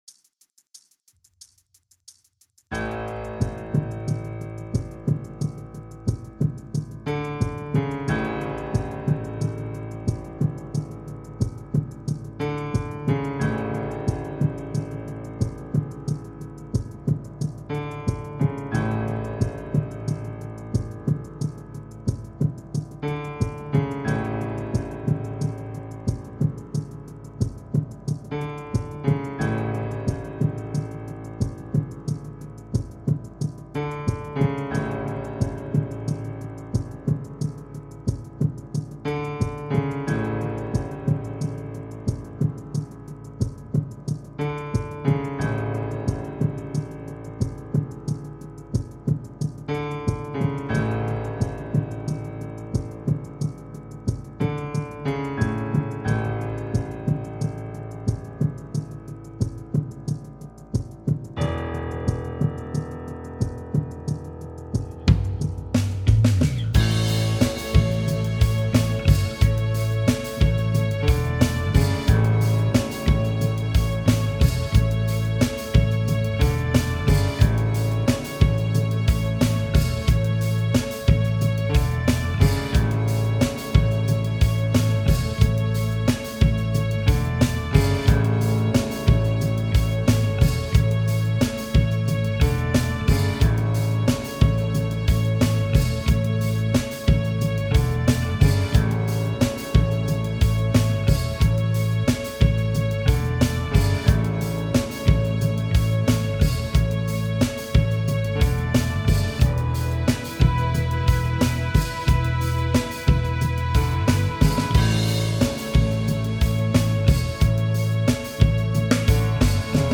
BPM : 90
Tuning : E
Without vocals